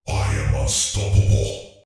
Subject description: Some highly electronic hero unit voice resources!
These voices were self generated by me, and I carried out a series of complex follow-up work to make them highly electronic and magnetic.